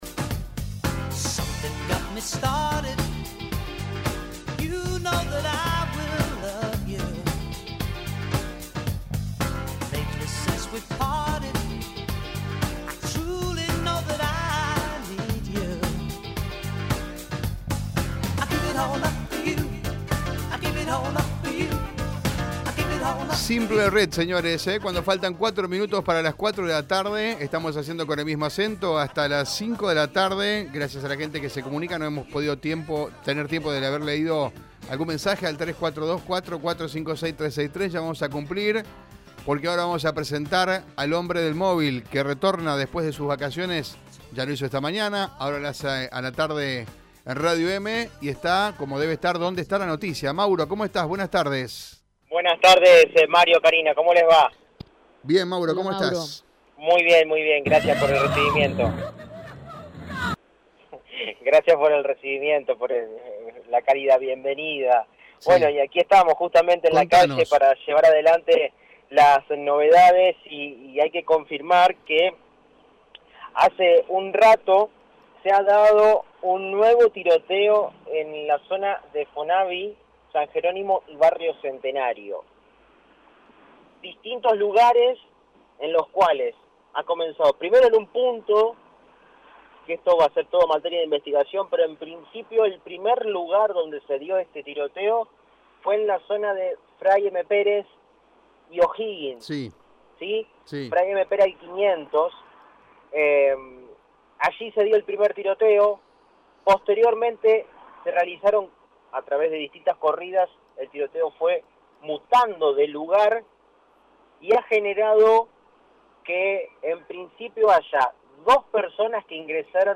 Según el móvil de Radio EME, dos personas resultaron heridas tras la confrontación que ocurrió en Fray M. Pérez y O’Higgins, en la zona sur de la capital provincial.